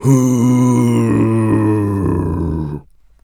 Male_Long_Moan_02.wav